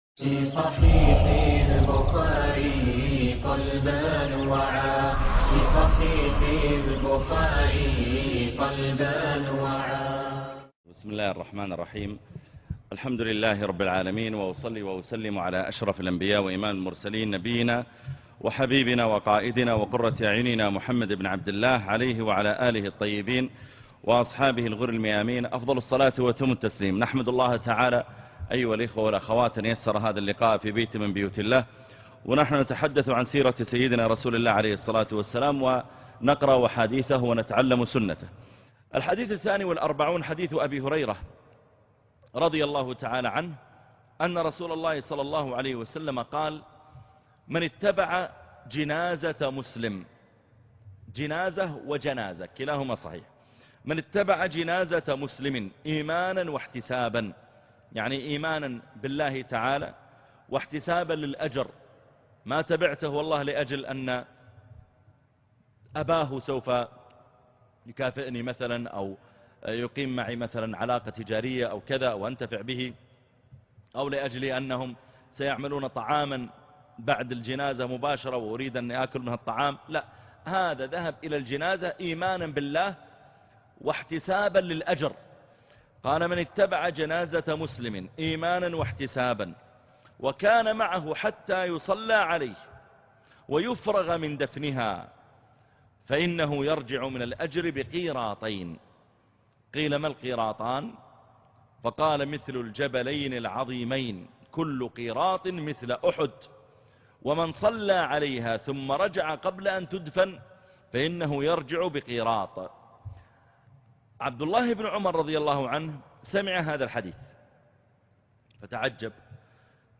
شرح مختصر الحديث للصحيح البخارى الدرس 43 - الشيخ محمد العريفي